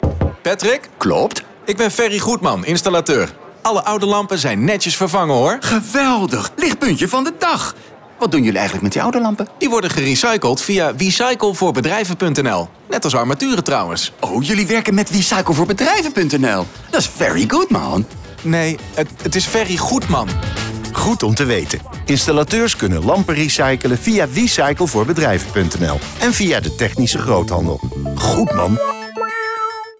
wecyclevoorbedrijven-radiocommercial | Wecycle pages